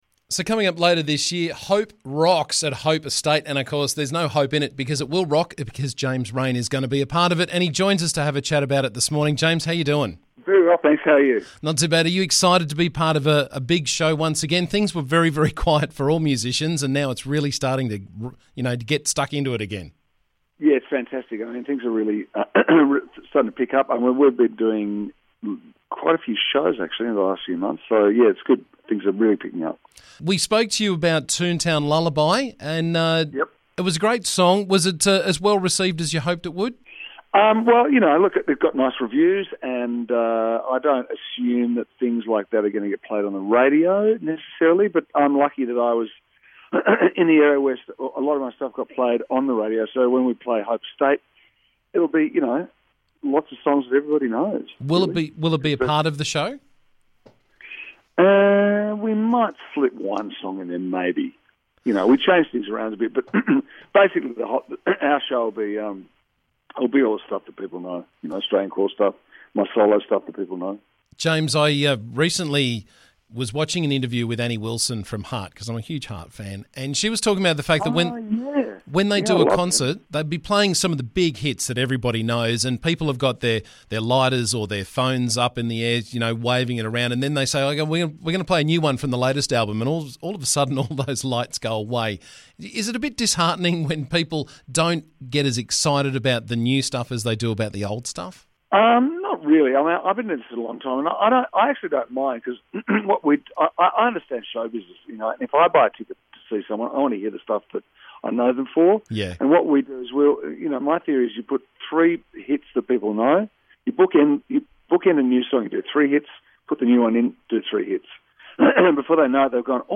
Tickets are on sale now for Hope Rocks on Saturday October 16 and I caught up with James Reyne this morning to to chat about the gig.